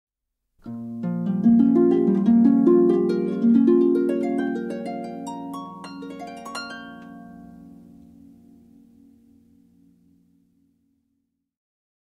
Sound Buttons: Sound Buttons View : Harp
harp_2i1dfLf.mp3